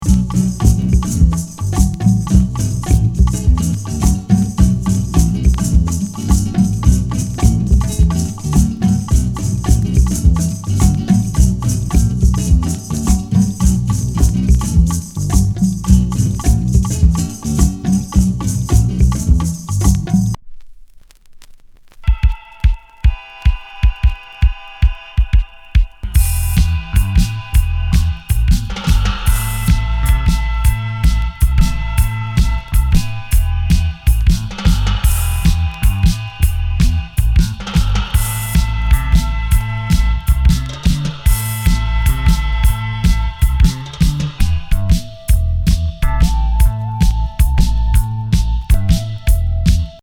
実験的ローファイNW！！